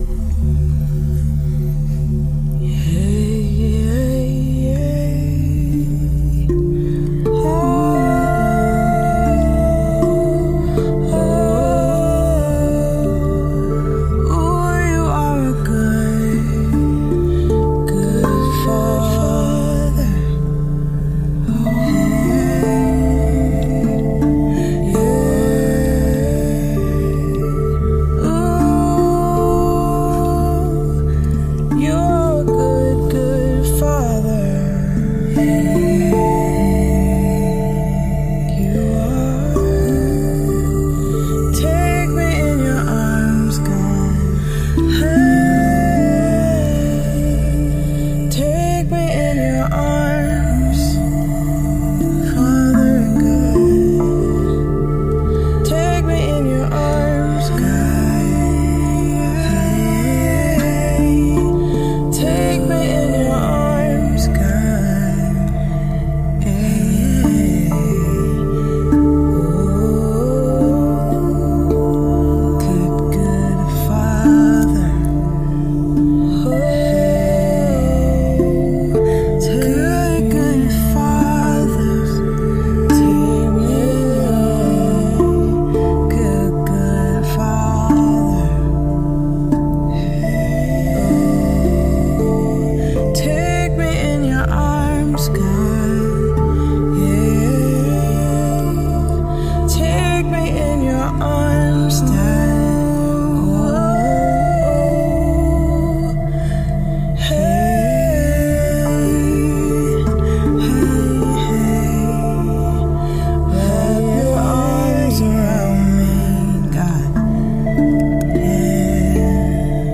Spontaneous Song: Take Me In Your Arms 3-13-25